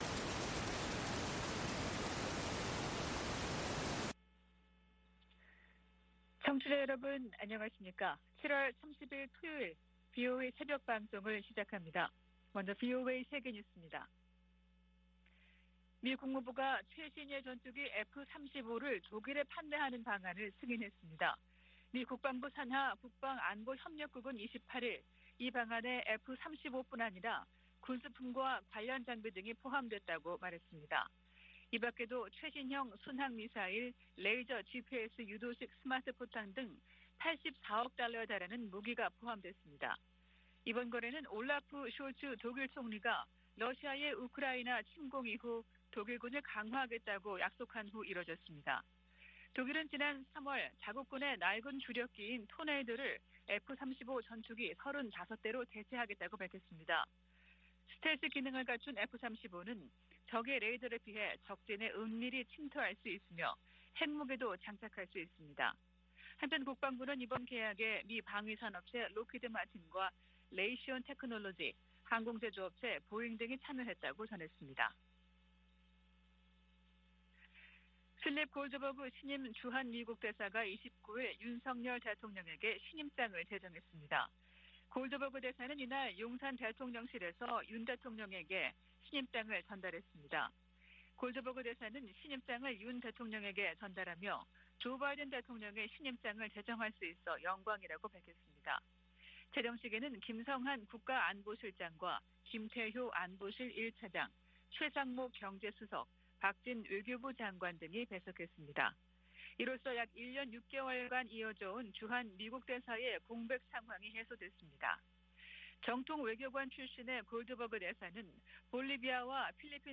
VOA 한국어 '출발 뉴스 쇼', 2022년 7월 30일 방송입니다. 미 국무부는 김정은 국무위원장의 전승절 기념행사 연설에 직접 반응은 내지 않겠다면서도 북한을 거듭 국제평화와 안보에 위협으로 규정했습니다. 핵확산금지조약(NPT) 평가회의에서 북한 핵 문제가 두 번째 주부터 다뤄질 것이라고 유엔 군축실이 밝혔습니다. 백악관 고위 관리가 북한이 미사일 자금 3분의 1을 사이버 활동으로 마련한다며 대응을 강화할 것이라고 말했습니다.